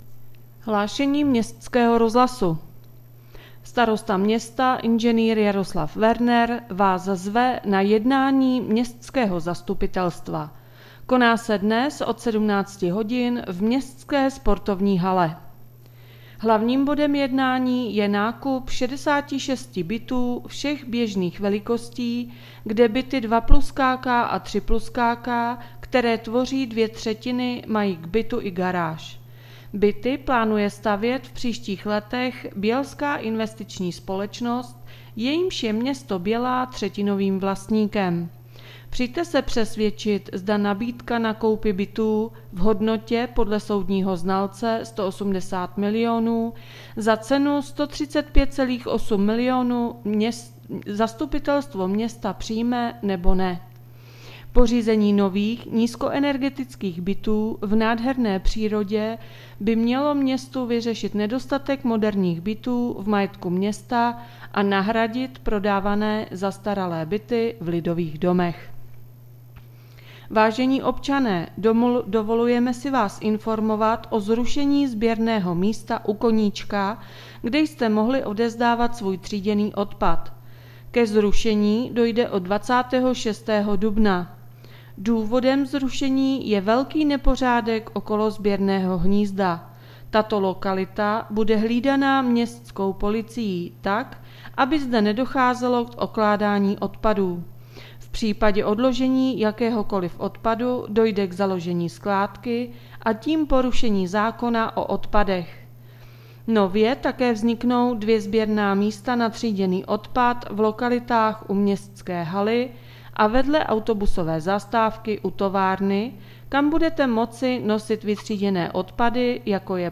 Hlášení městského rozhlasu 21.4.2021